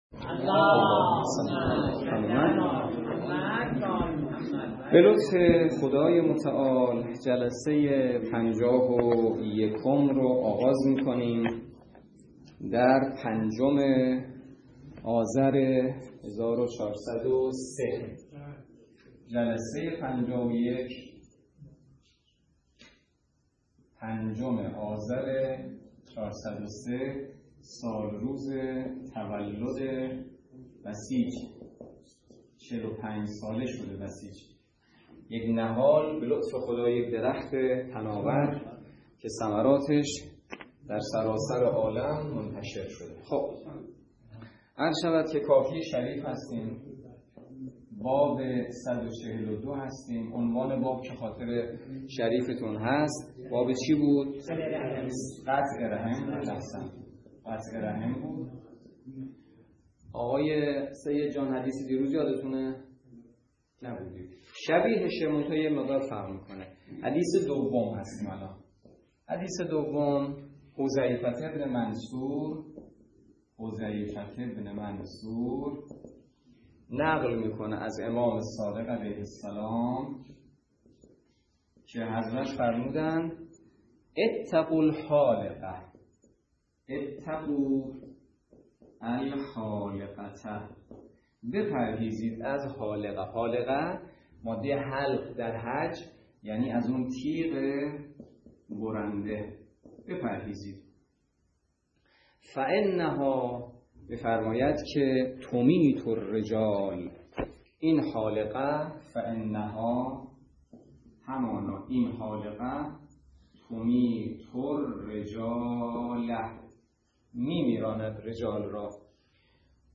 درس فقه الاجاره نماینده مقام معظم رهبری در منطقه و امام جمعه کاشان - سال سوم جلسه پنجاه و یک